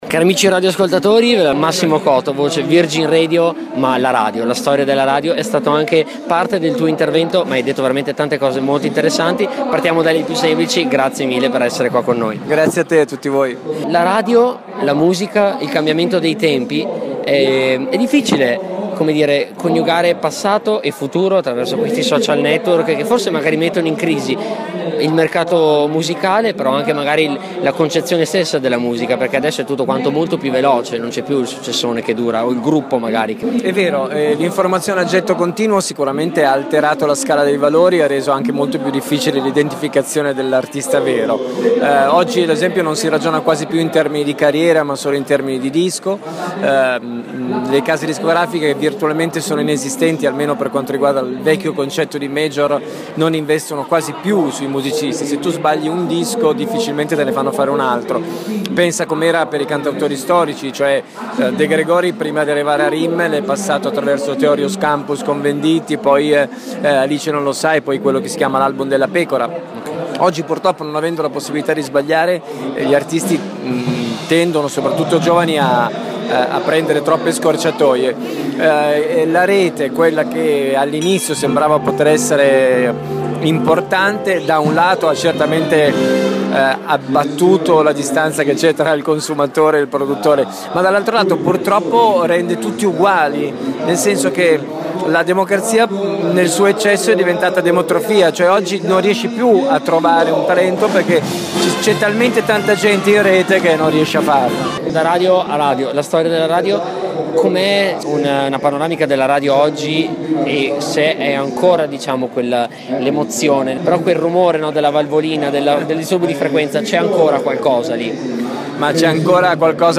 Abbiamo intervistato per voi Omar Fantini, presentatore della serata e comico televisivo, e Massimo Cotto, tra le personalità di spicco della radiofonìa Italiana e della tv.